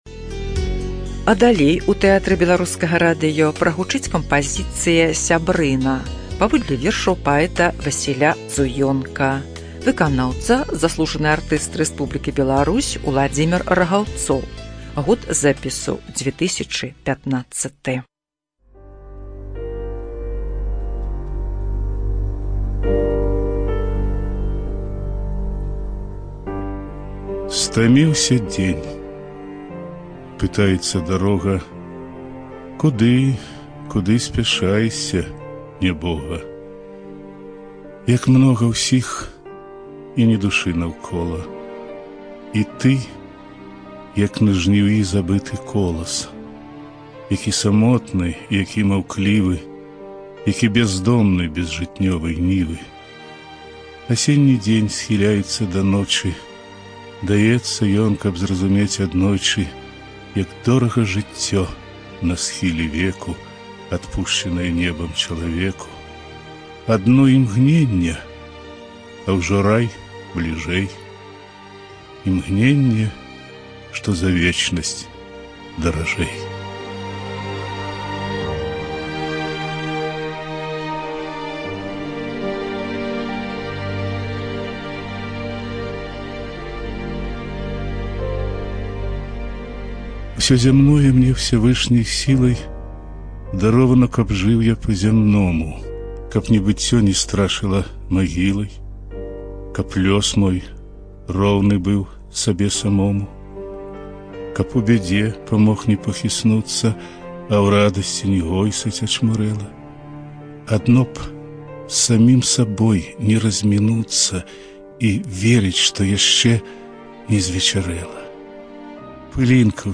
ЖанрПоэзия, Книги на языках народов Мира